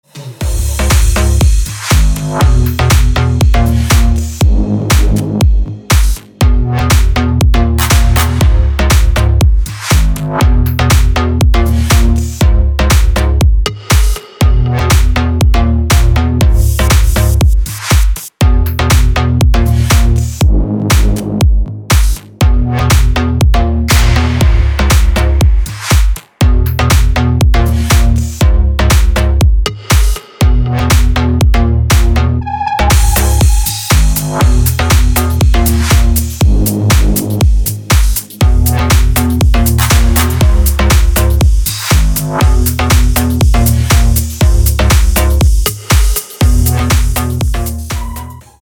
громкие
жесткие
электронная музыка
мощные басы
без слов
future house
качающие
G-House